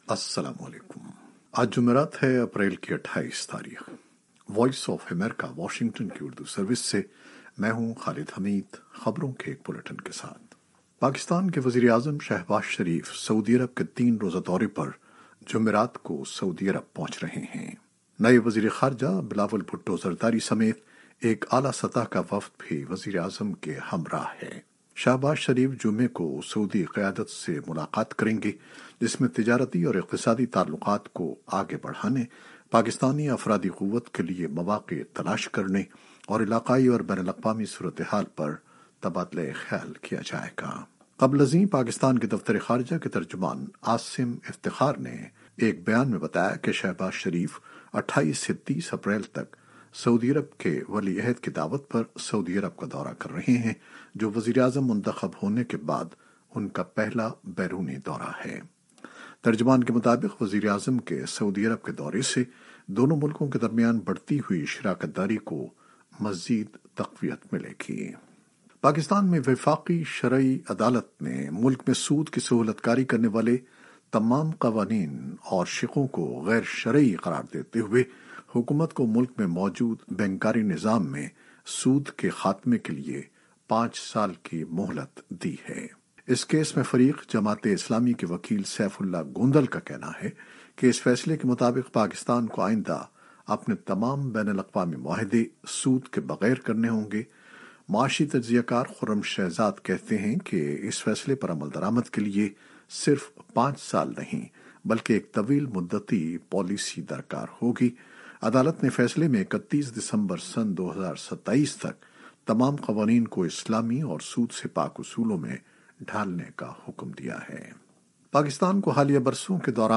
نیوز بلیٹن 2021-28-04